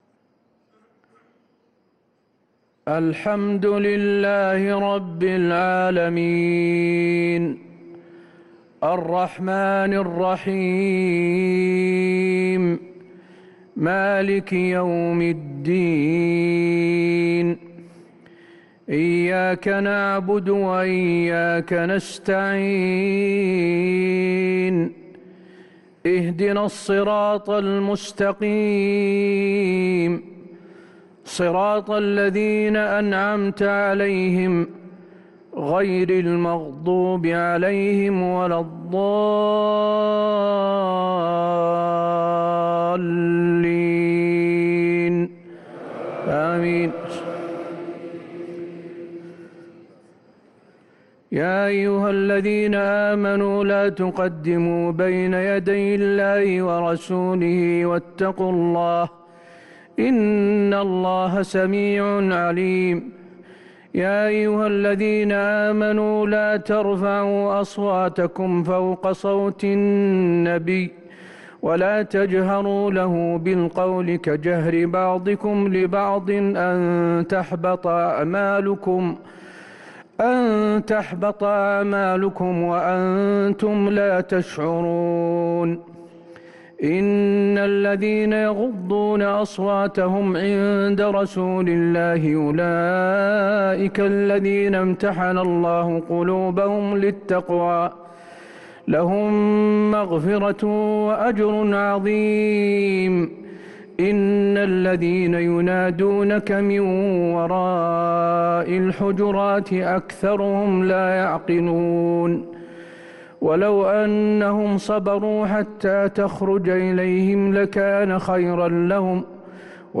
صلاة العشاء للقارئ حسين آل الشيخ 8 ذو الحجة 1444 هـ
تِلَاوَات الْحَرَمَيْن .